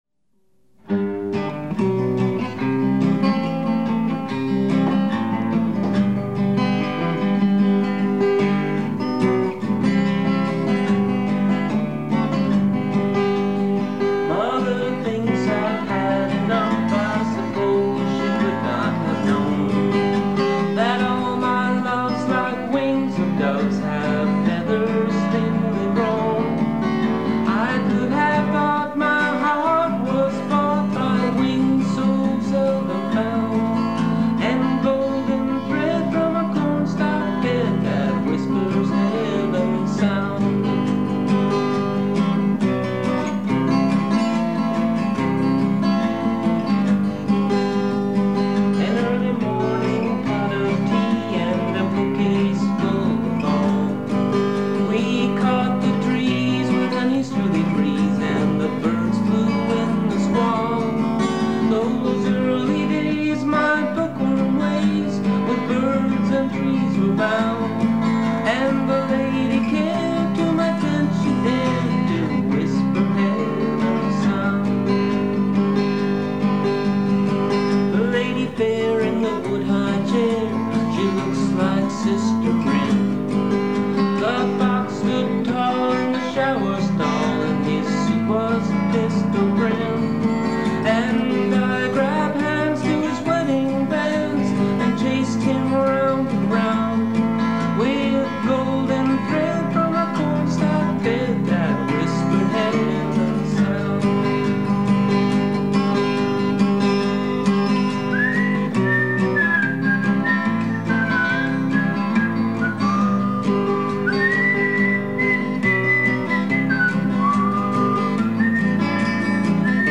Heavens-Sound-Studio-Version.mp3